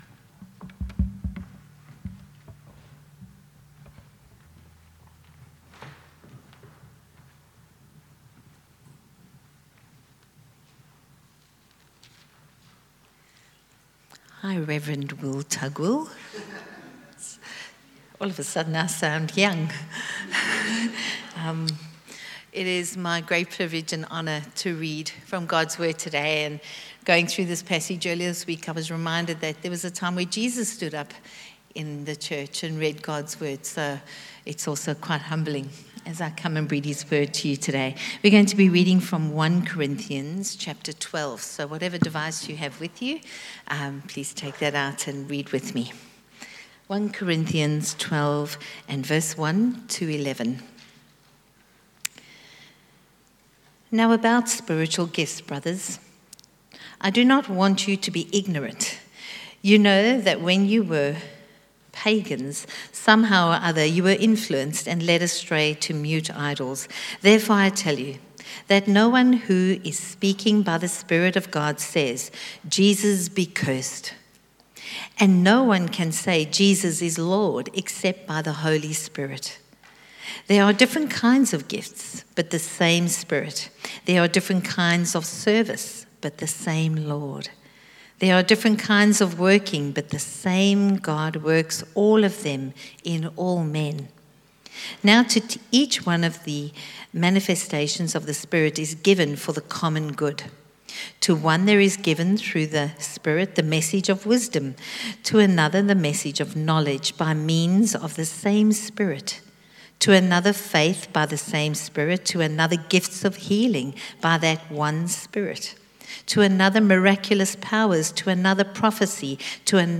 Service Type: 4PM